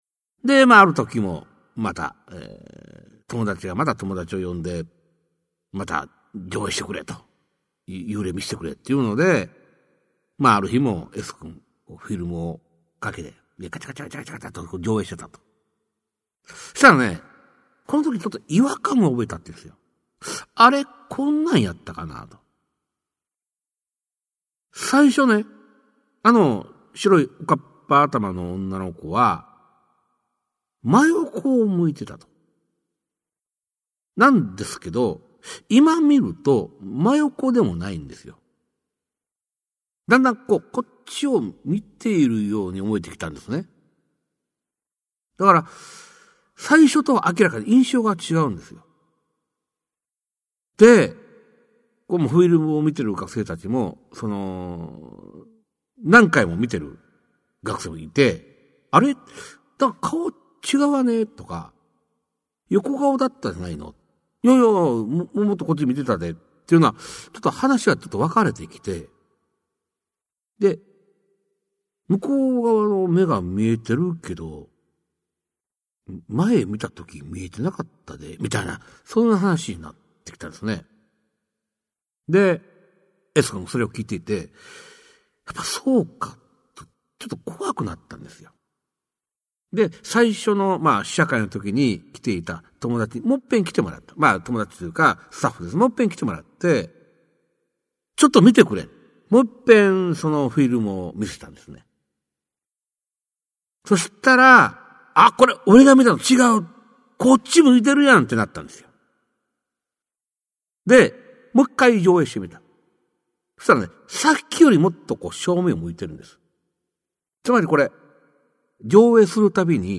[オーディオブック] 市朗怪全集 五十四
実話系怪談のパイオニア、『新耳袋』シリーズの著者の一人が、語りで送る怪談全集! 1990年代に巻き起こったJホラー・ブームを牽引した実話怪談界の大御所が、満を持して登場する!!